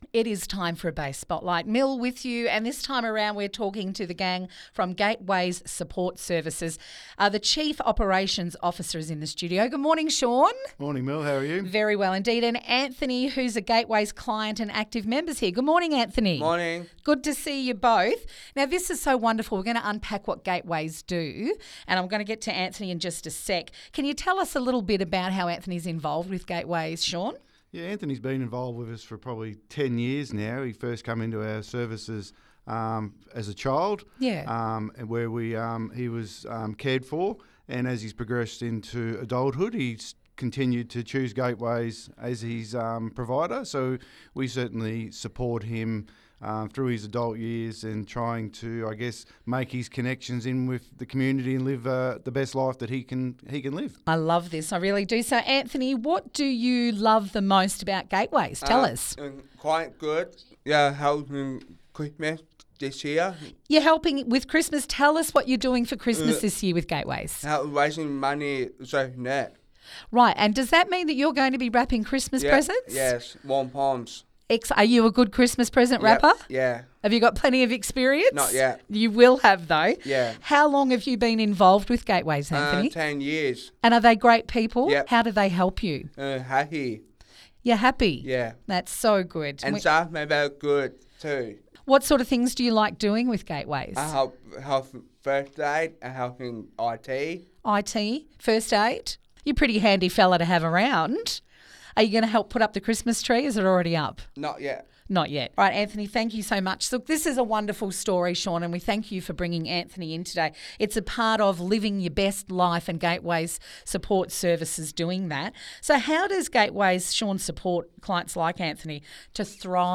Bay Fm Interview